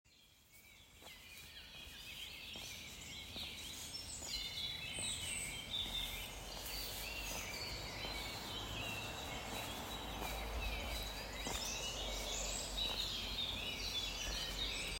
I could not ask for a better incentive, as I got out of my car, than the magnificent bird tweeting that filled the main street, downtown Sintra.
These are the real tweets that we should be paying attention to.